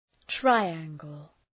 Προφορά
{‘traı,æŋgəl}